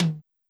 CLF Tom 2.wav